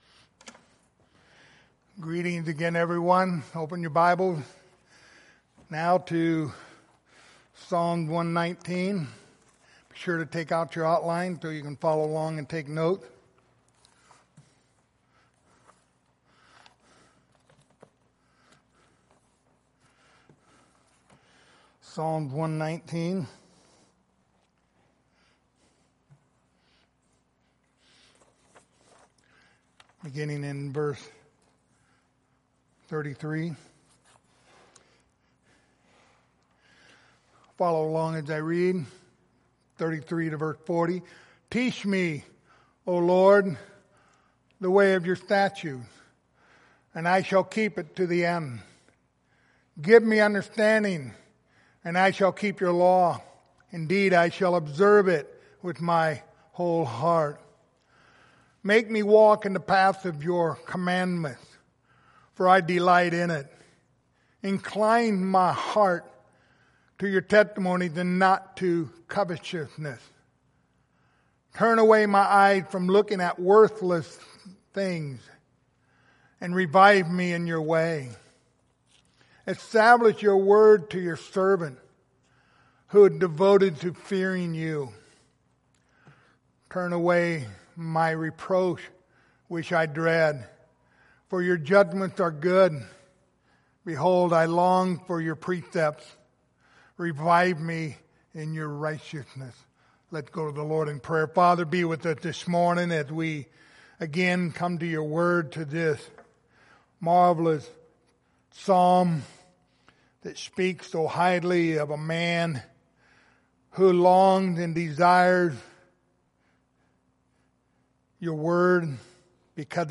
Psalm 119 Passage: Psalms 119:33-40 Service Type: Sunday Morning Topics